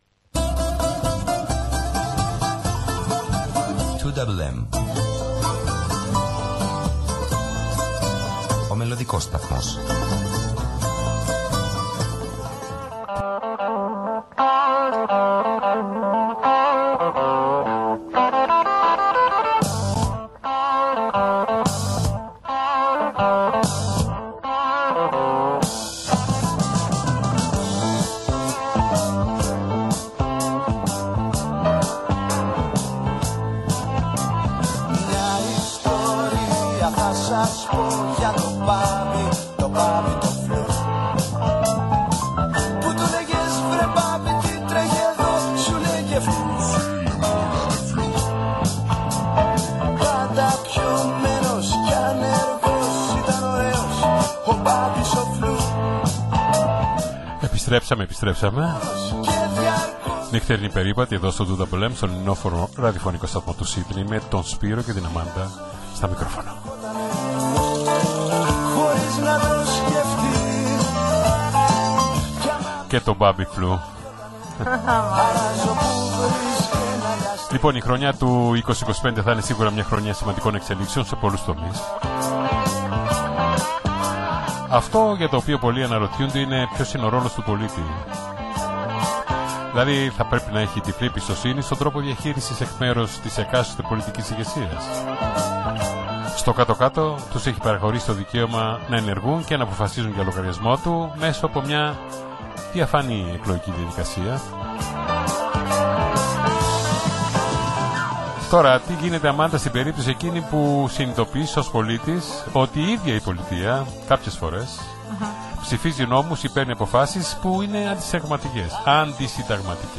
Στην διάρκεια της ραδιοφωνικής συνέντευξης